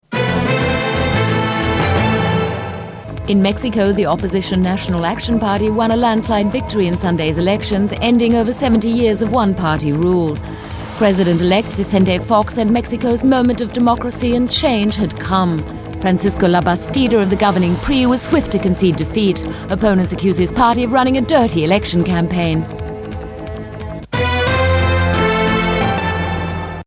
englische (uk) Sprecherin, Muttersprache.
Sprechprobe: Industrie (Muttersprache):